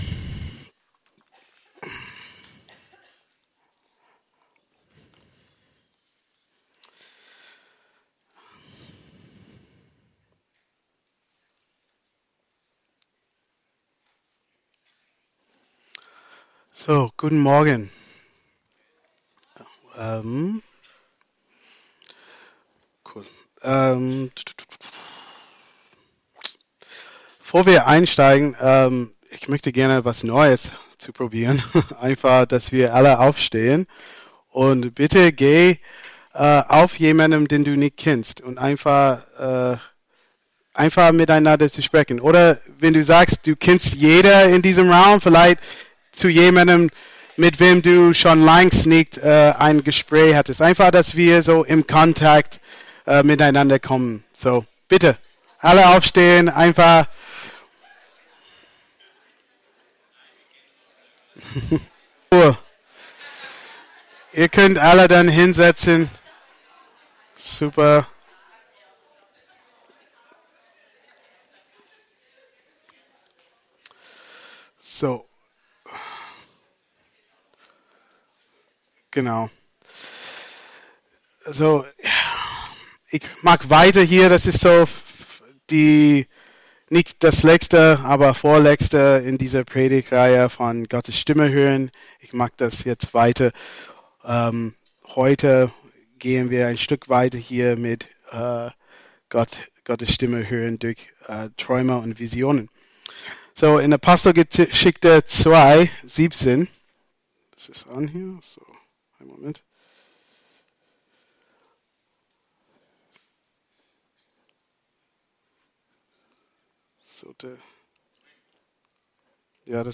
Predigt vom 02.03.2025 – Christliches Zentrum Günzburg
Predigt vom 02.03.2025